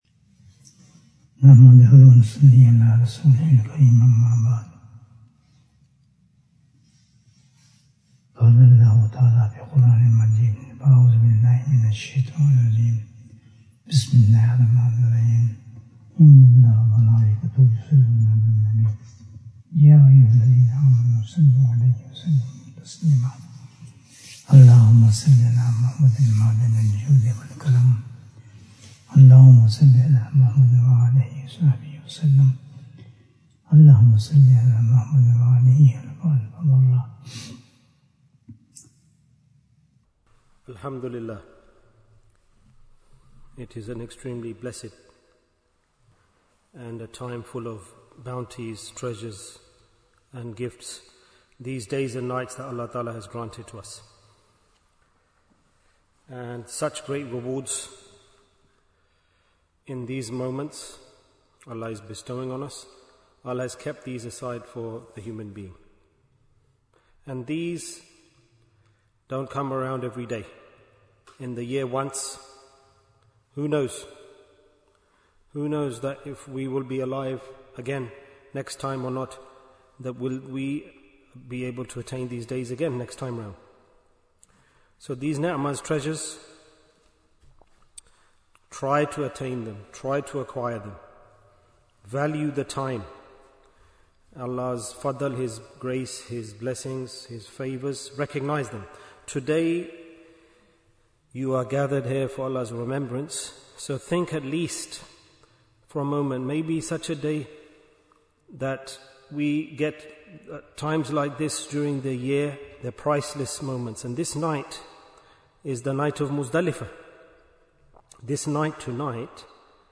Oh Neglectful Seek Forgiveness Bayan, 20 minutes5th June, 2025